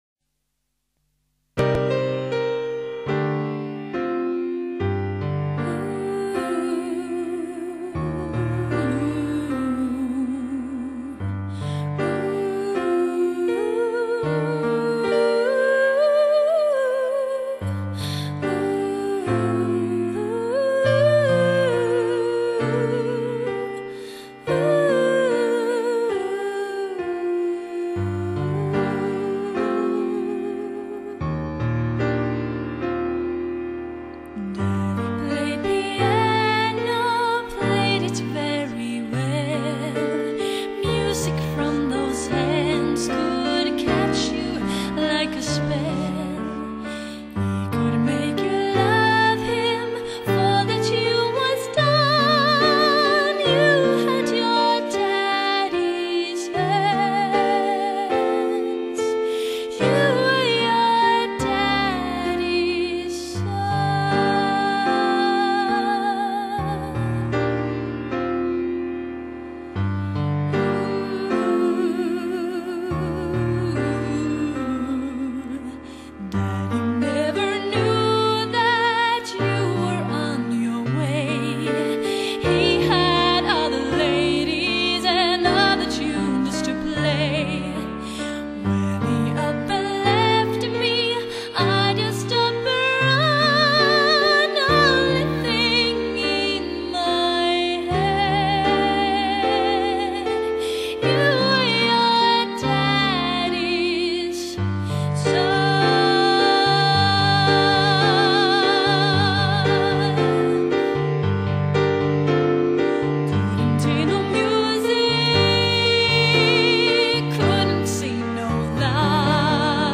Studioinspelning